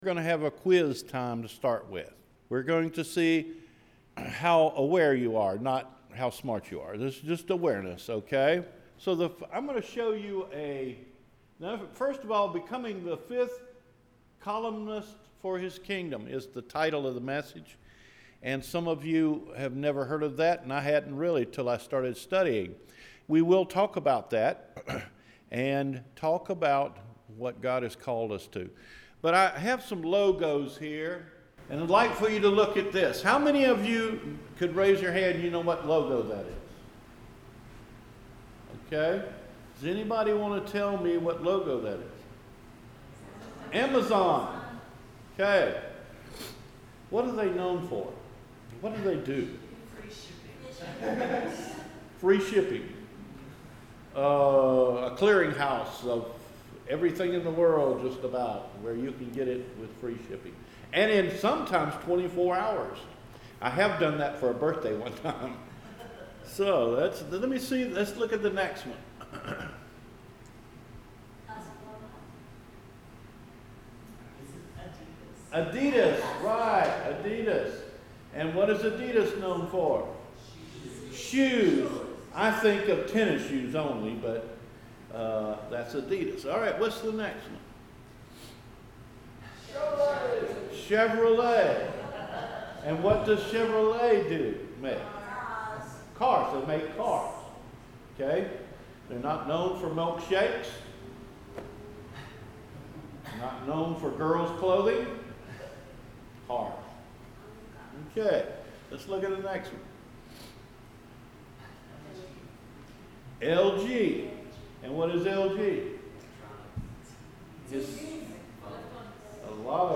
Becoming ‘Fifth Columnists for His Kingdom’ – August 20, 2017 Sermon